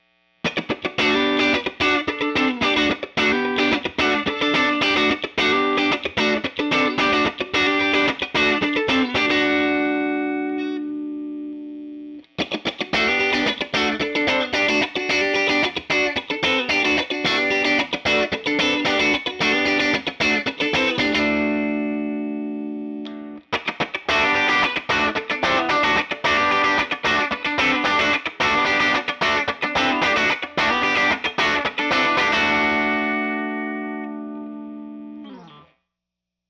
1959 Tele Set Vox AC30 / Celestion AlNiCo Blue Speakers - Neck Middle Bridge